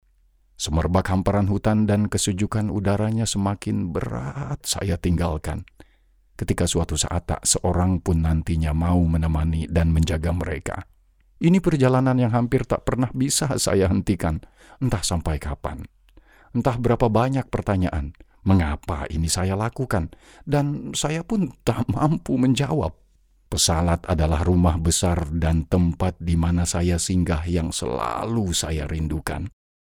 Commercial, Deep, Natural, Versatile, Corporate
Explainer
Many people say that his voice is distinctive, strongly masculine, and pleasant to listen to.